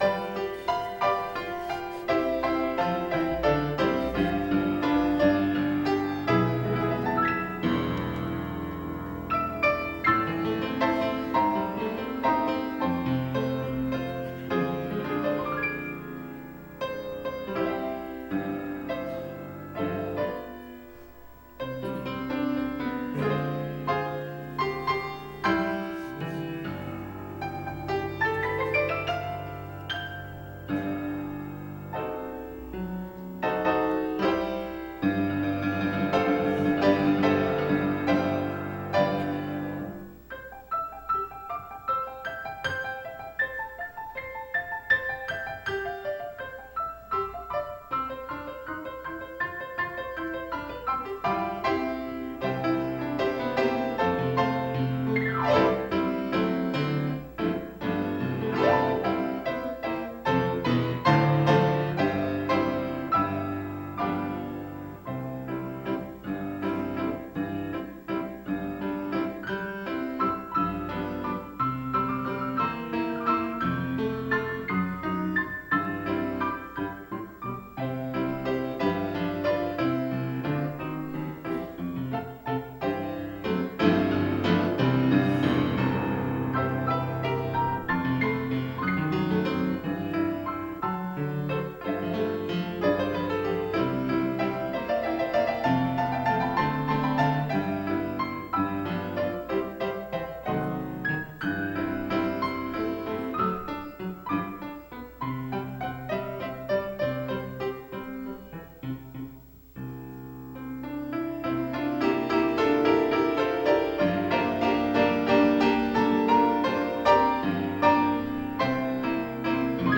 at our Orchestra Concert in March of 2013
pianist